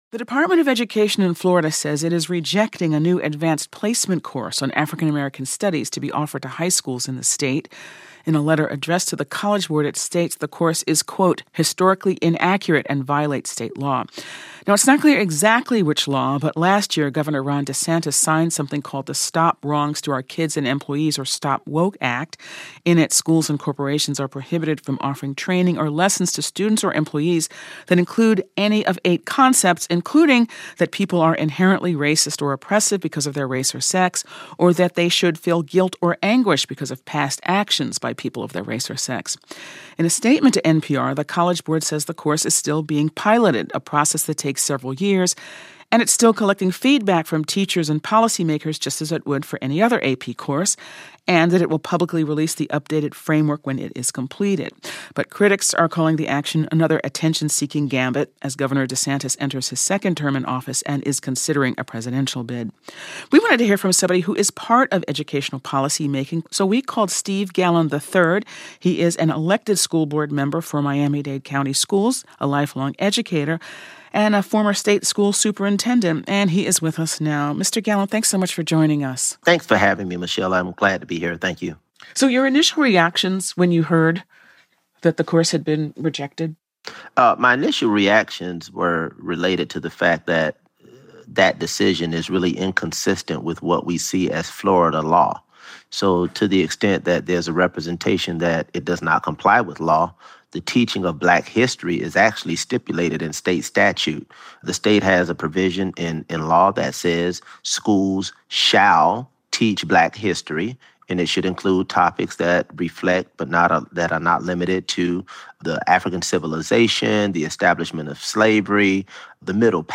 NPR's Michel Martin talks to Steve Gallon, school board member for Miami-Dade County Schools, about the state education department's ban of a new Advanced Placement course on African American studies.